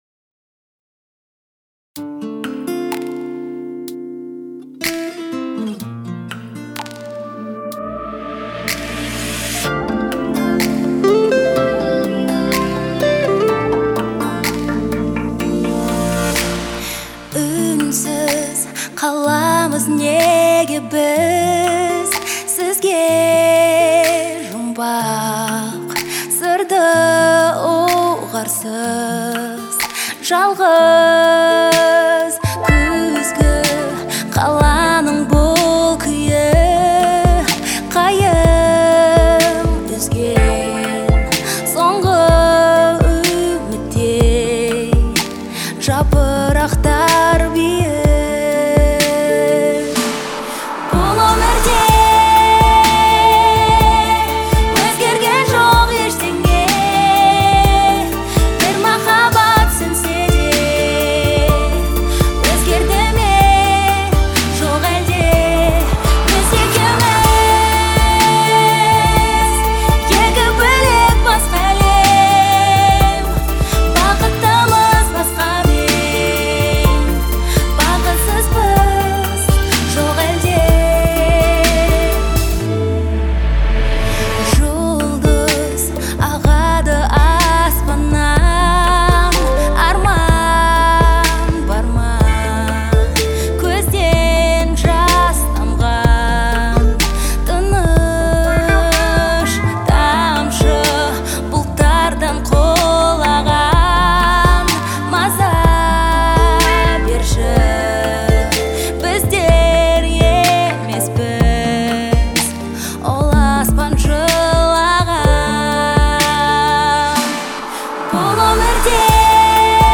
казахстанской певицы
В звучании выделяются мелодичные синтезаторы и нежный голос